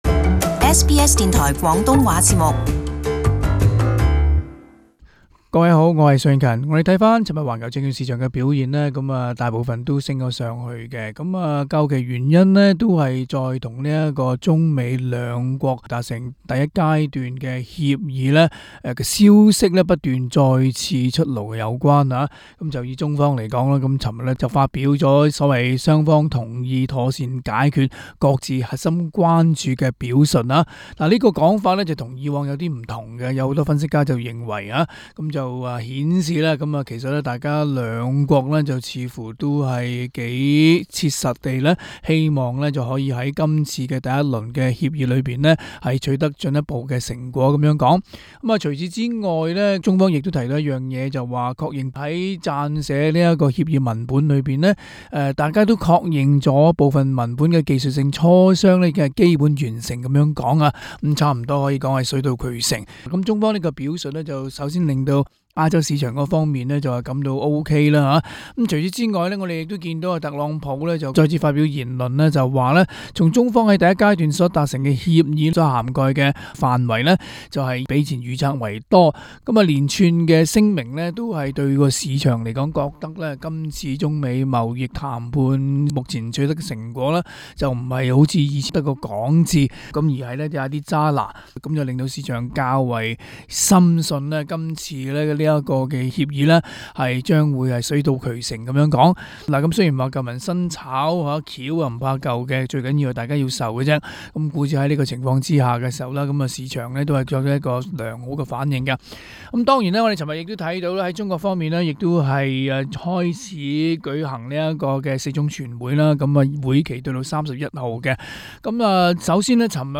Source: AAP, SBS SBS广东话播客 View Podcast Series Follow and Subscribe Apple Podcasts YouTube Spotify Download (18.36MB) Download the SBS Audio app Available on iOS and Android 10月28日周一，寰球证券市场表现不俗，记录得全綫上升。